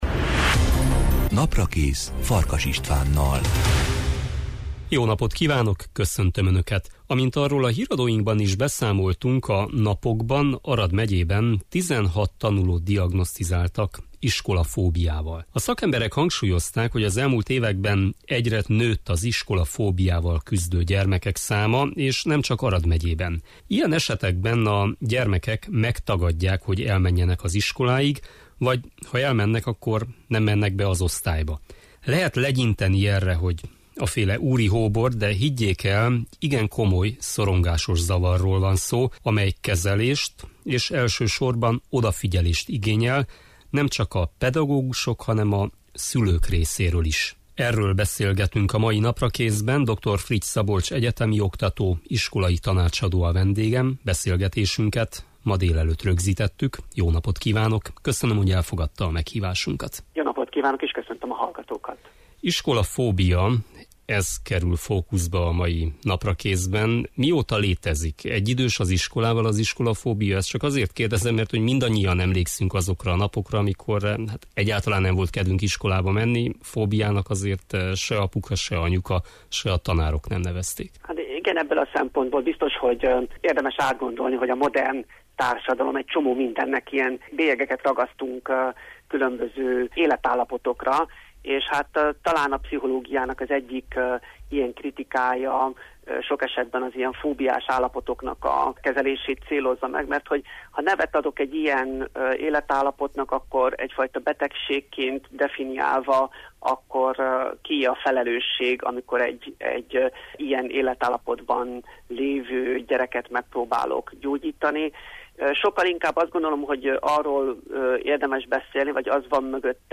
Lehet legyinteni erre, hogy úri hóbort, de higgyék el, igen komoly szorongásos zavarról van szó, amely kezelést és elsősorban odafigyelést igényel, nemcsak a pedagógusok, hanem a szülők részéről is. A mai Naprakészben erről beszélgetünk.